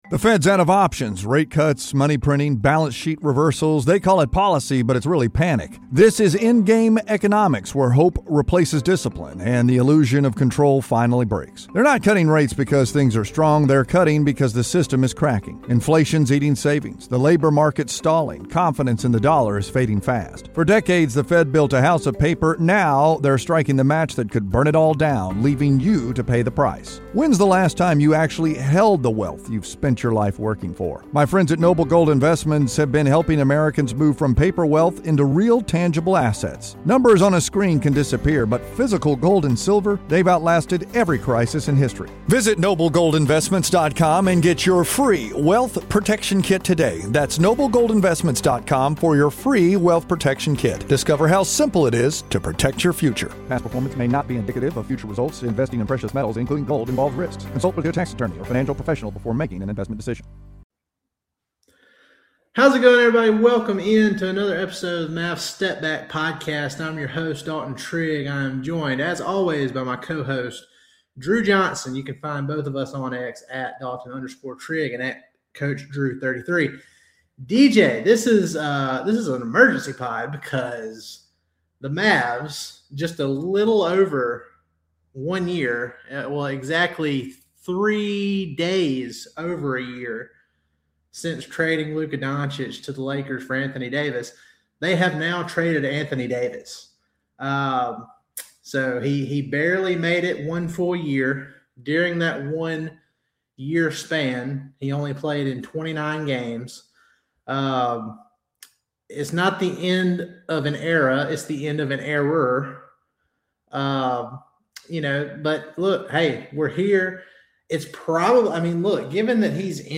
The guys give their raw, immediate reactions to the Dallas Mavericks trading Anthony Davis to the Washington Wizards and what it will mean for the Cooper Flagg era going forward!